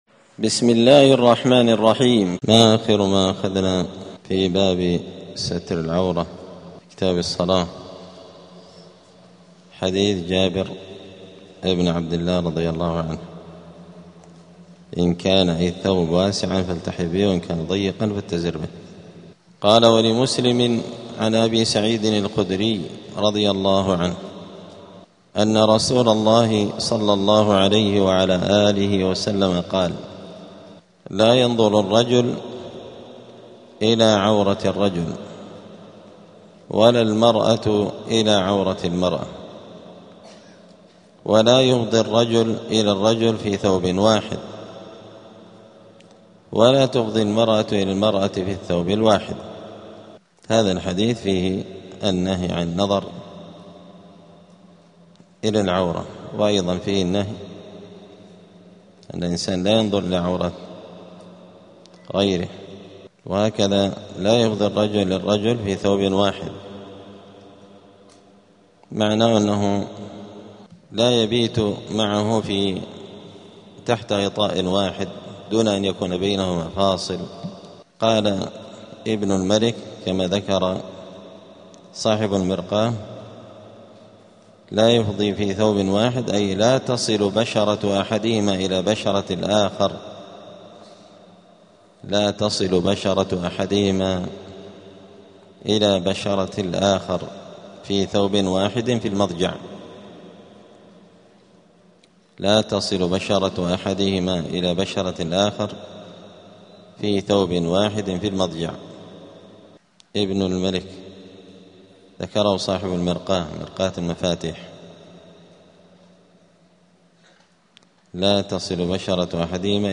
دار الحديث السلفية بمسجد الفرقان قشن المهرة اليمن
*الدرس الواحد والستون بعد المائة [161] باب ستر العورة {تحريم نظر الرجل إلى عورة الرجل}*